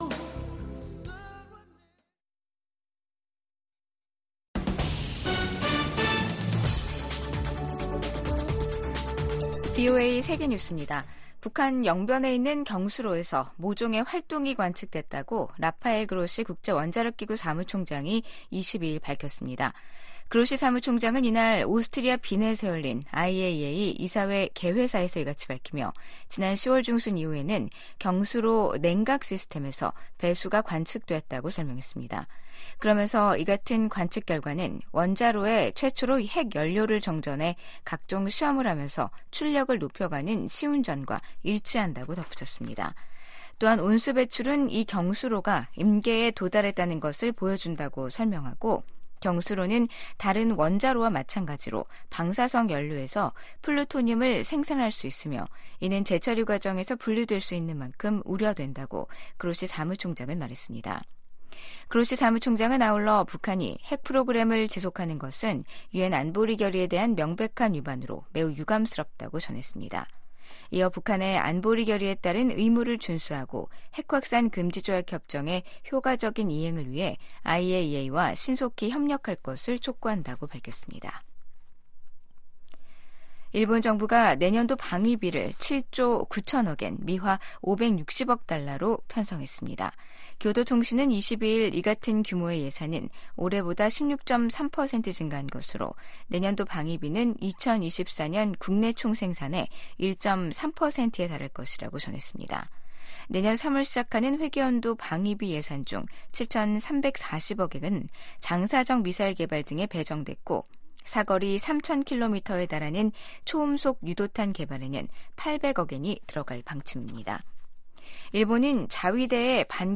VOA 한국어 간판 뉴스 프로그램 '뉴스 투데이', 2023년 12월 22 일 3부 방송입니다. 미국이 탄도미사일 개발과 발사가 방위권 행사라는 북한의 주장을 '선전이자 핑계일 뿐'이라고 일축했습니다. 미 국방부는 북한 수뇌부를 제거하는 '참수작전'이 거론되자 북한에 대해 적대적 의도가 없다는 기존 입장을 되풀이했습니다. 이스라엘 정부가 북한의 탄도미사일 발사를 '테러 행위'로 규정했습니다.